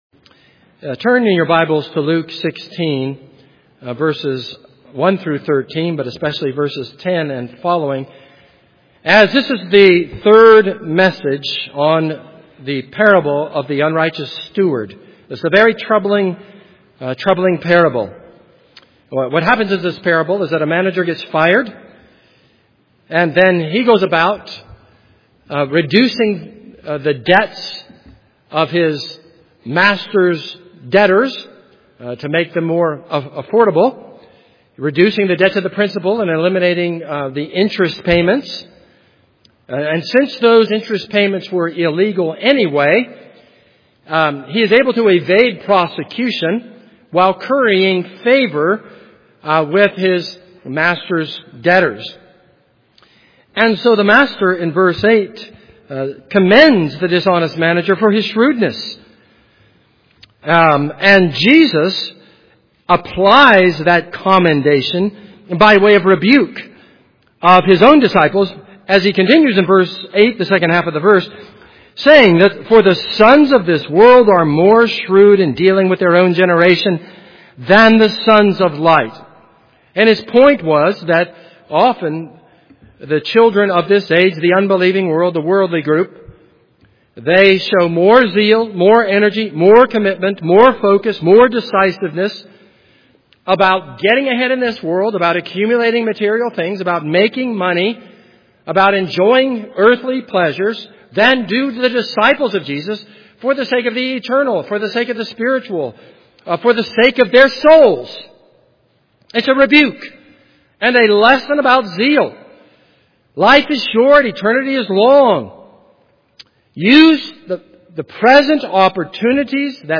This is a sermon on Luke 16:1-13.